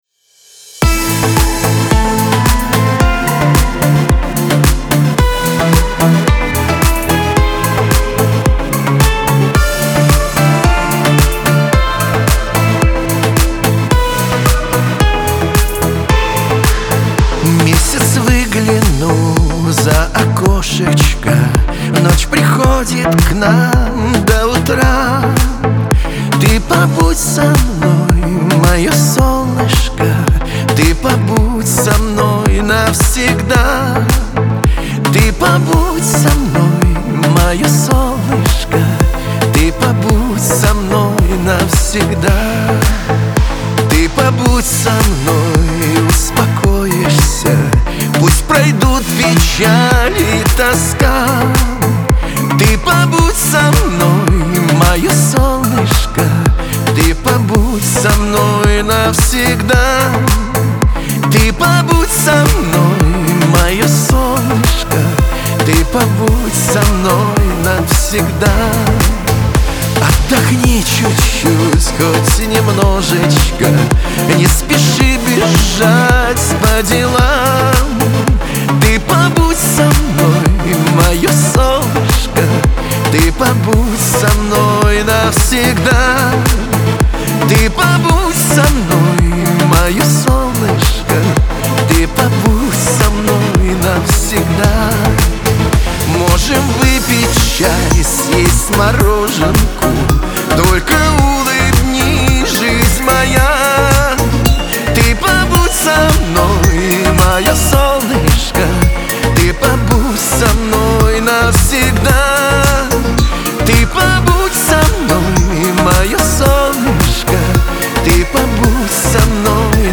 Лирика
Шансон
эстрада